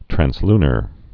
(trănslnər, trănz-, trăns-l-, trănz-)